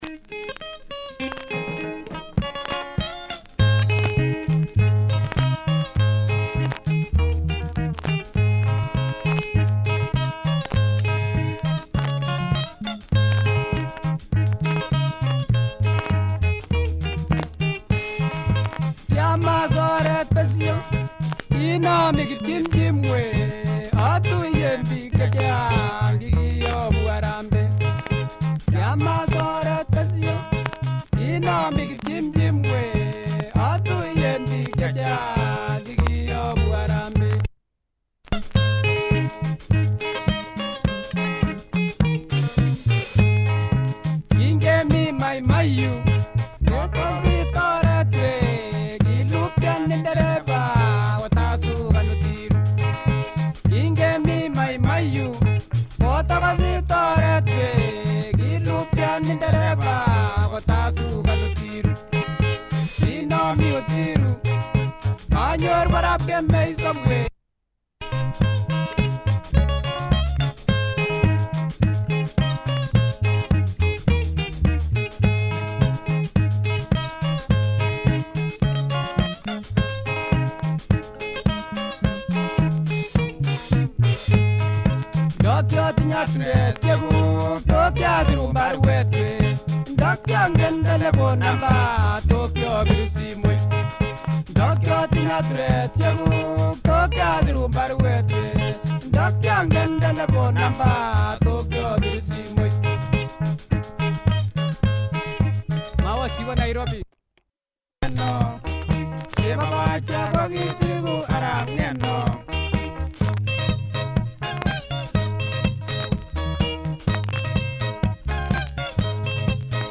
Traditional sounds from the Kipsigis ethnic group.